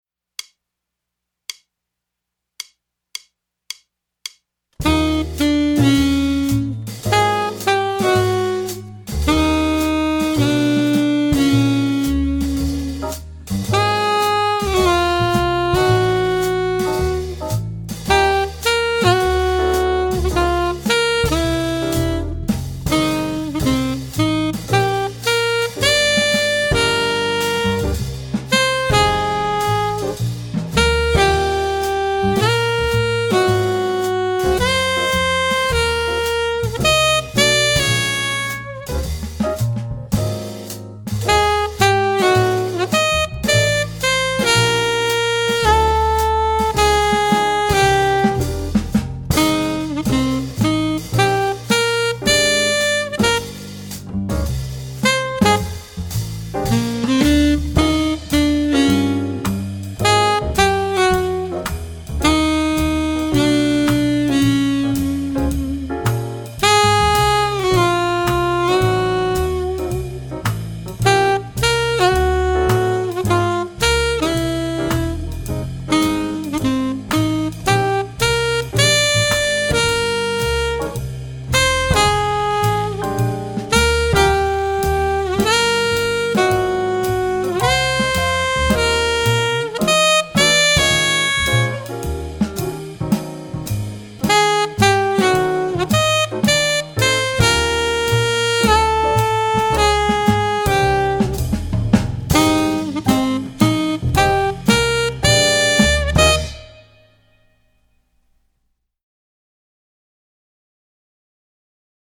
Voicing: Jazz Saxophone M